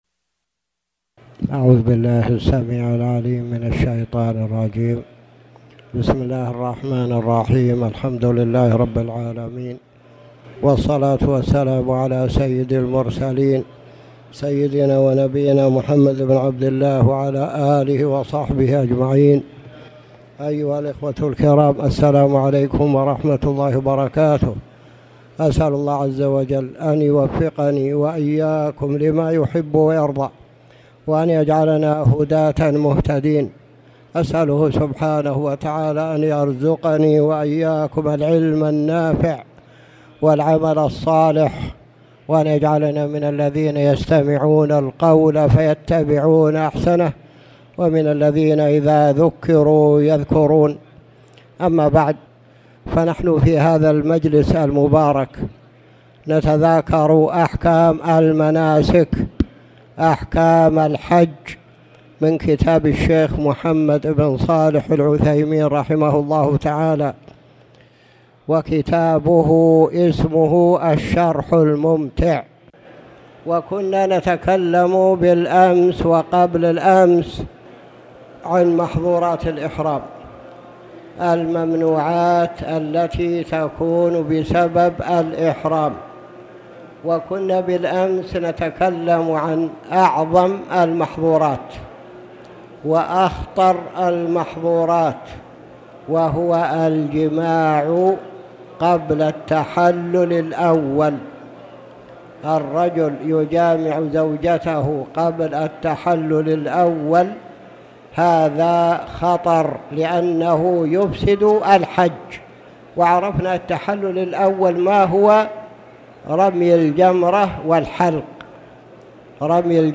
تاريخ النشر ٢٩ ذو القعدة ١٤٣٨ هـ المكان: المسجد الحرام الشيخ